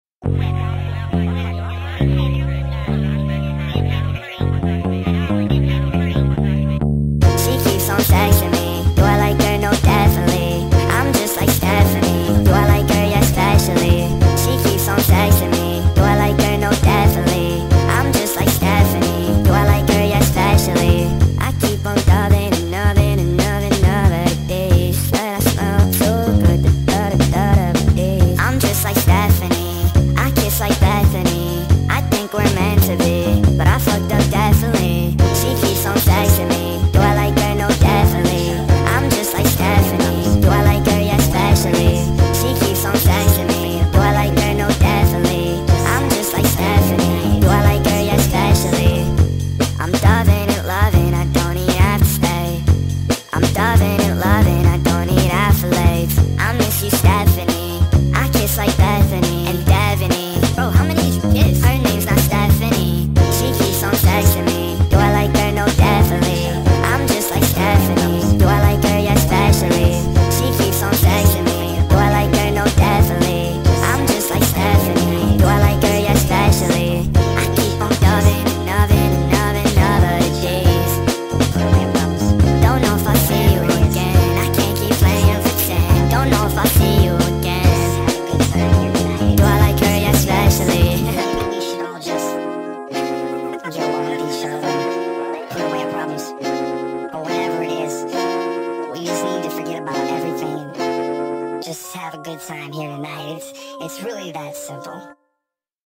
با ریتمی سریع شده